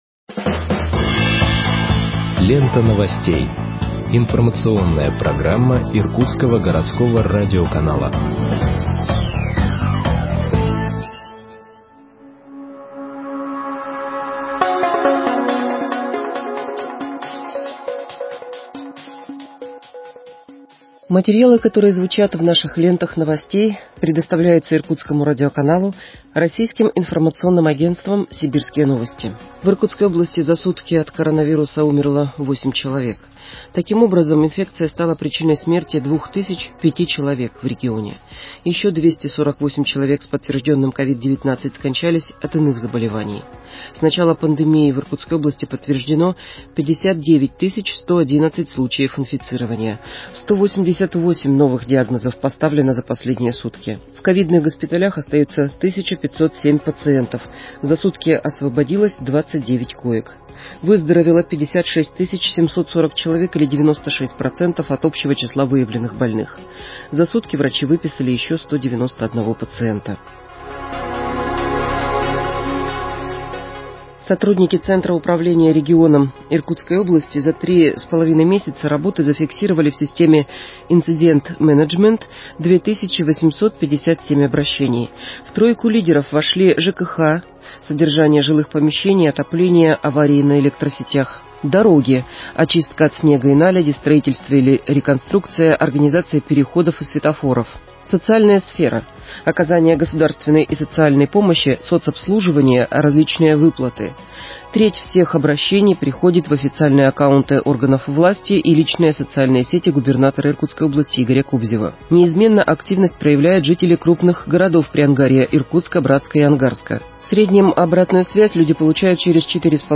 Выпуск новостей в подкастах газеты Иркутск от 16.03.2021 № 2